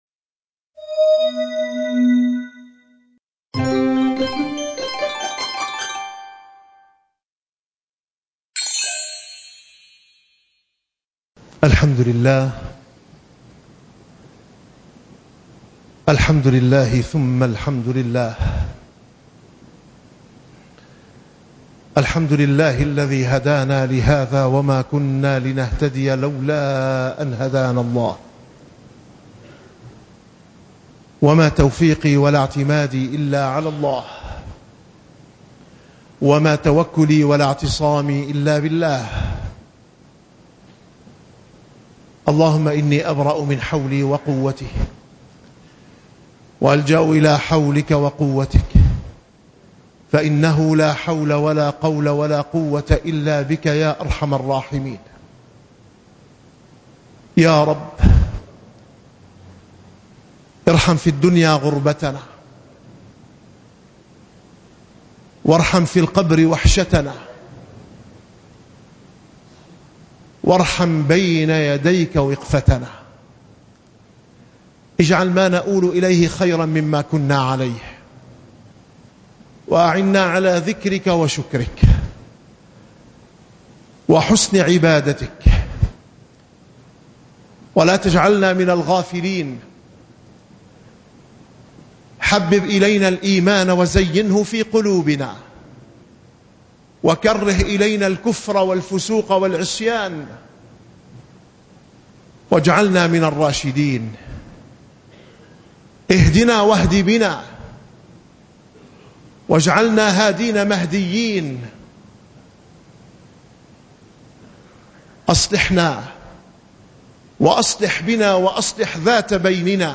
- الخطب -